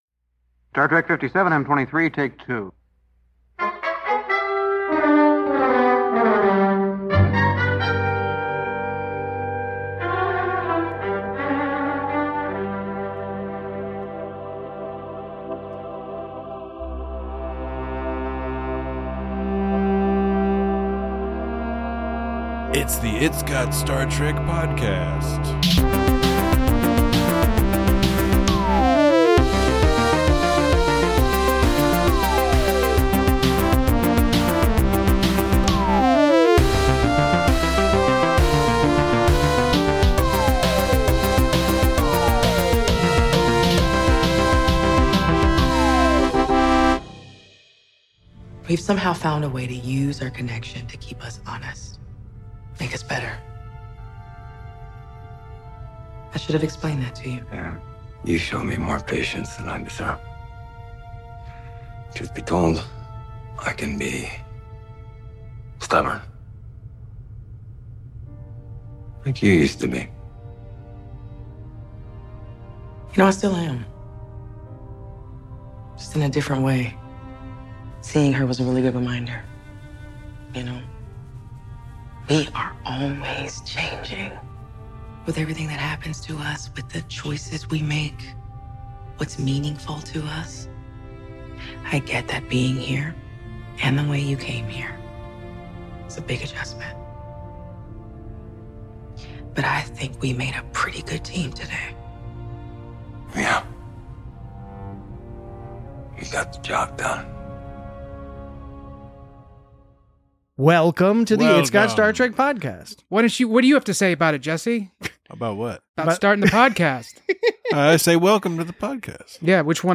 Join your temporally-displaced hosts as they discuss a wonderful twist on a well-worn time travel trope, the intrinsic value of social connection, and the playful joy that is a fully-invigorated Commander Stamets.